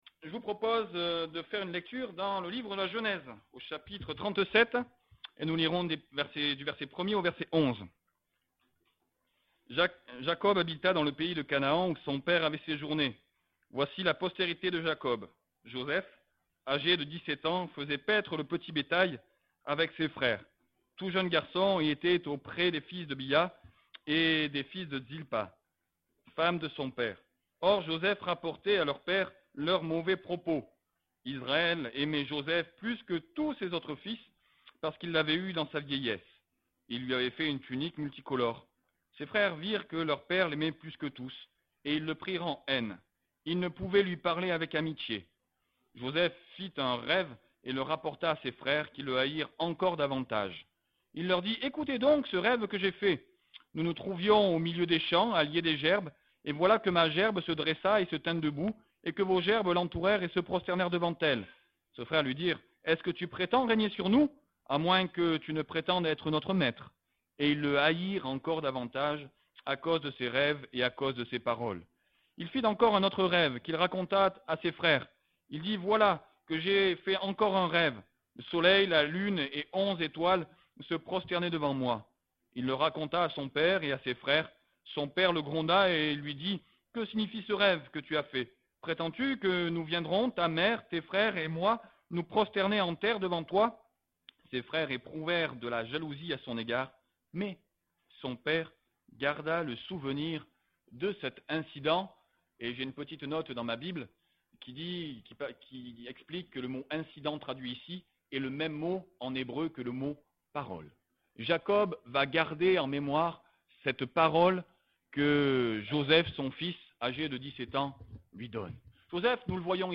Category: Message audio